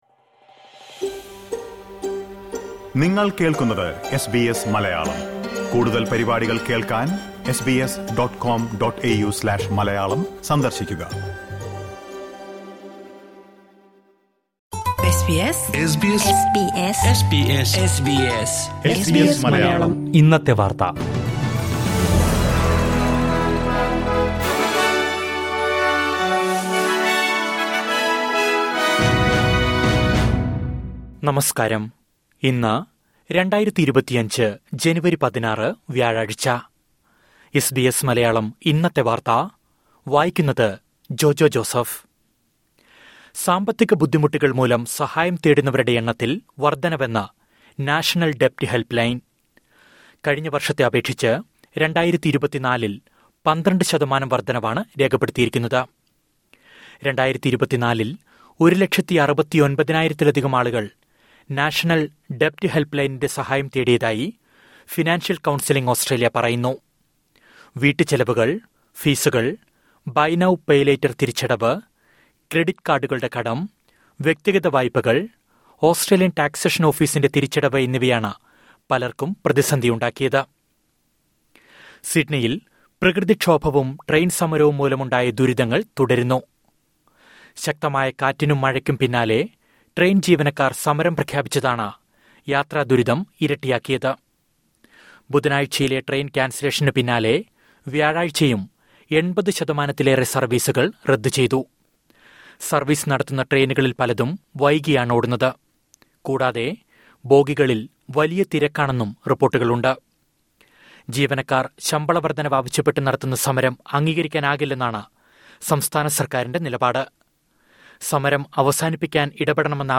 2025 ജനുവരി 16ലെ ഓസ്‌ട്രേലിയയിലെ ഏറ്റവും പ്രധാന വാര്‍ത്തകള്‍ കേള്‍ക്കാം...